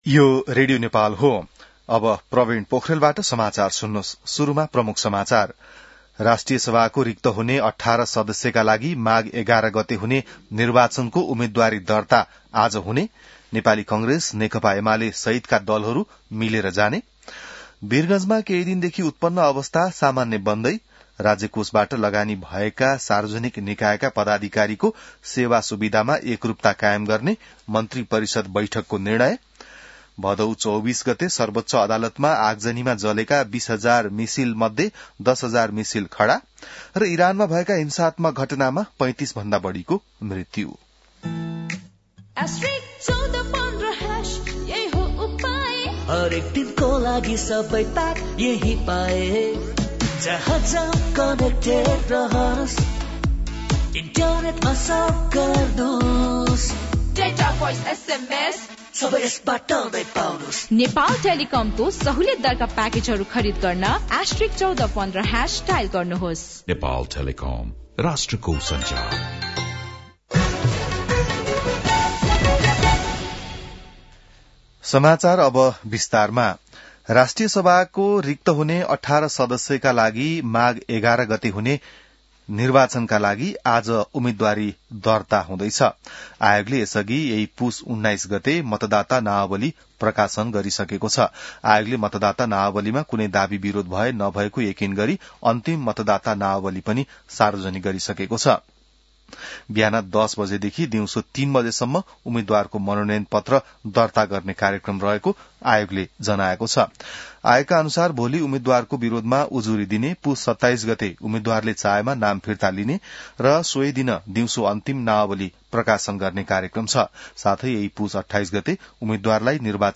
बिहान ७ बजेको नेपाली समाचार : २३ पुष , २०८२